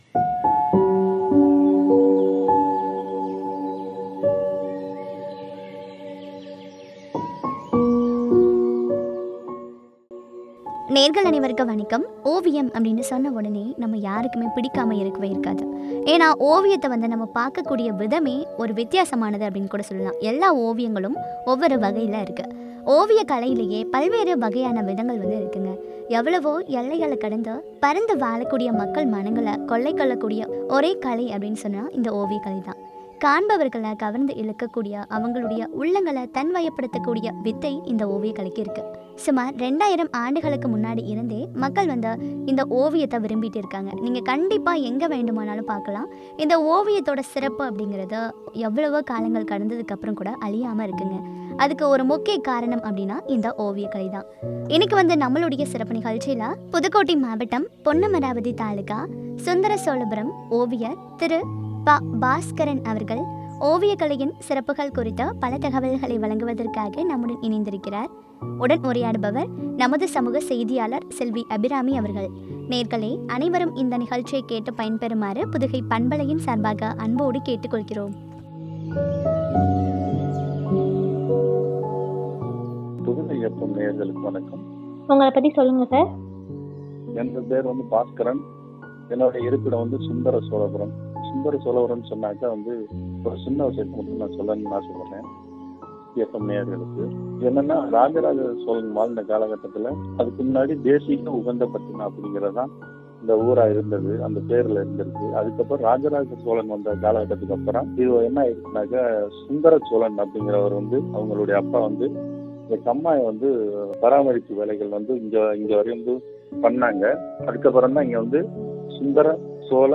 ஓவியக்கலையின் சிறப்புகள் பற்றிய உரையாடல்.